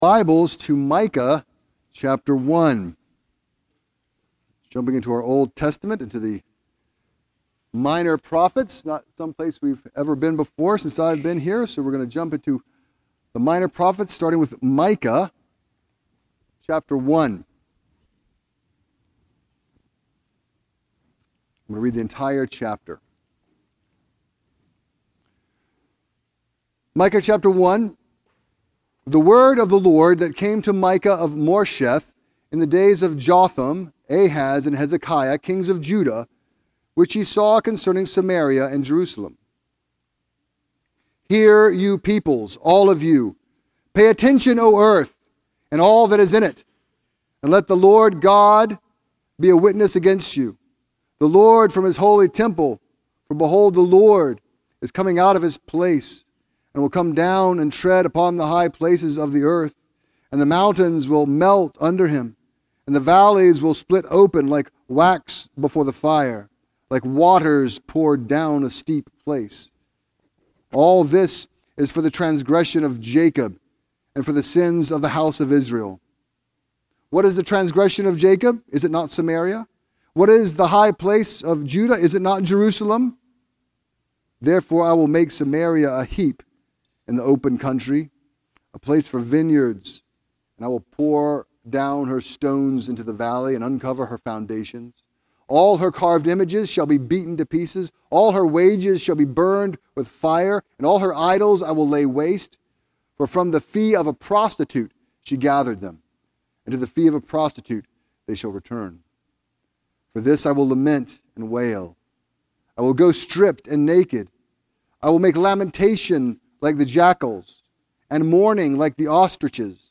A message from the series "Minor Prophets."